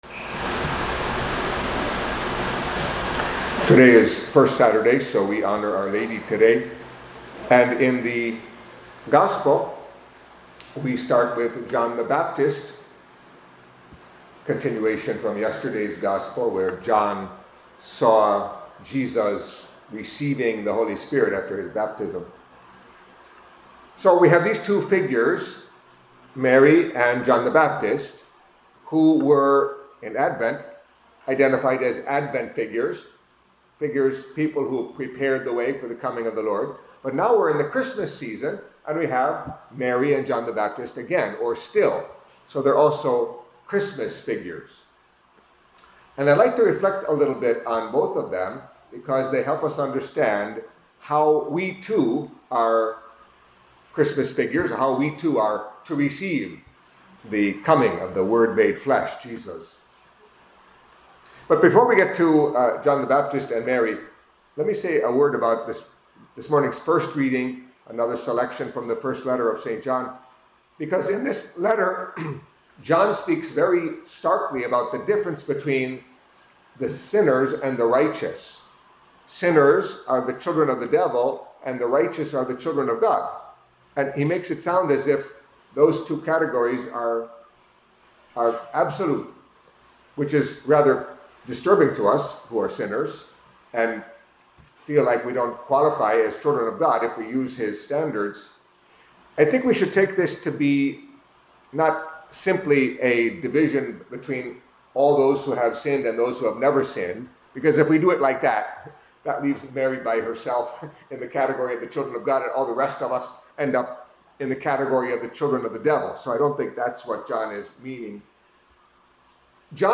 Catholic Mass homily for Saturday, Christmas Weekday